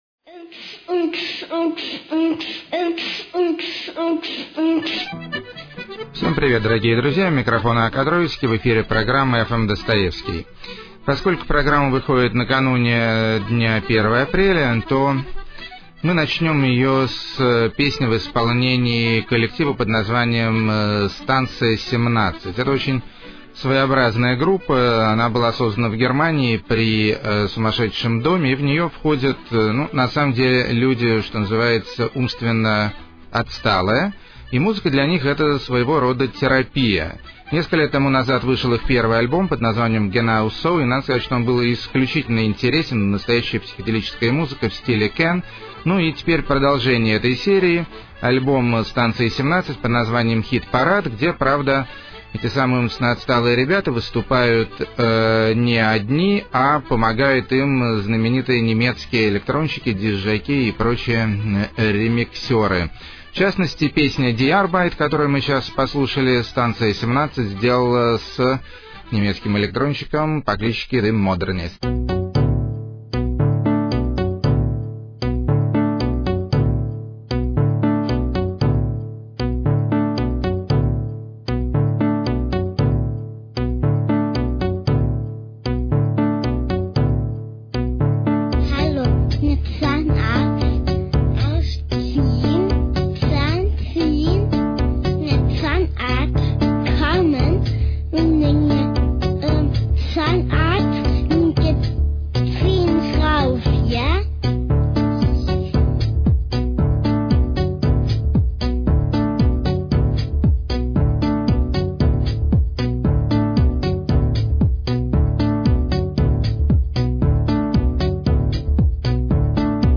Чистая Отборная Английская Психоделия.
Компьютерный Lounge С Уклоном В Морепродукты.
Гений Вокального Фламенко. 86 Лет.
Депрессо-кантри В Женском Изложении.